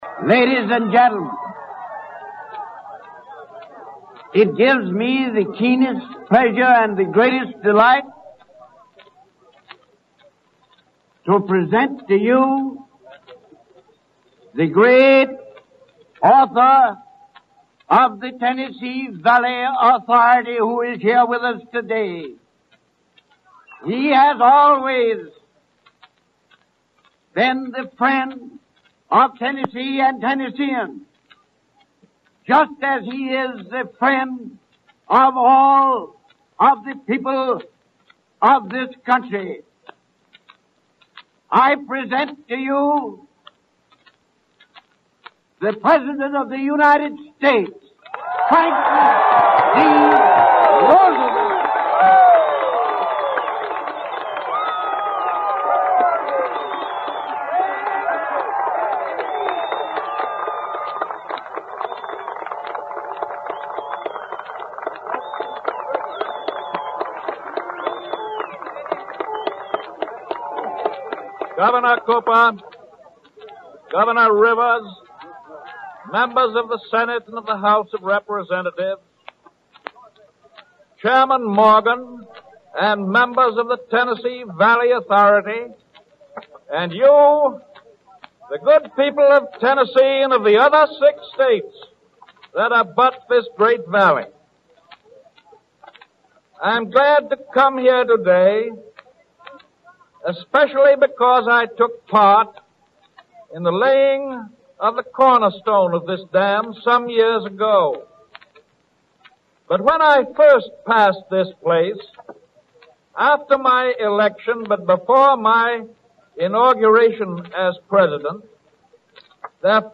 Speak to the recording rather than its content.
The tape is part of a WDOD broadcast from the Labor Day event.